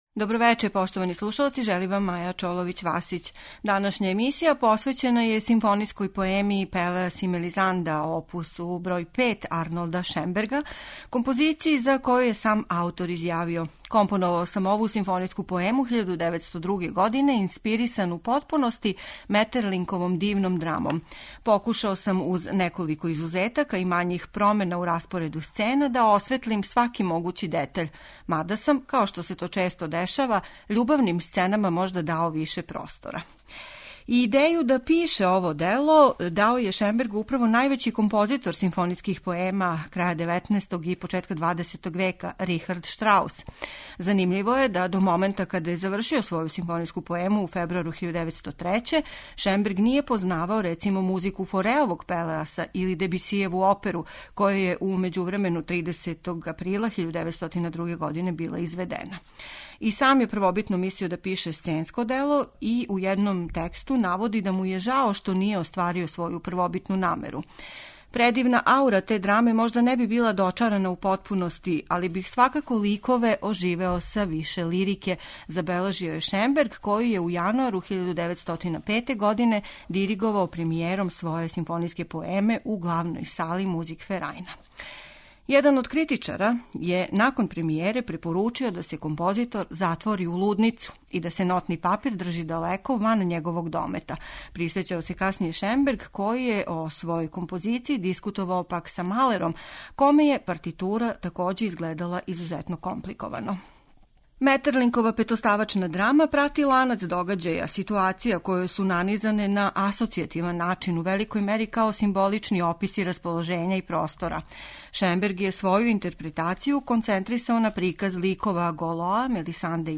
Музички језик ове поеме је у стилу позног романтизма, на ивици експресионизма.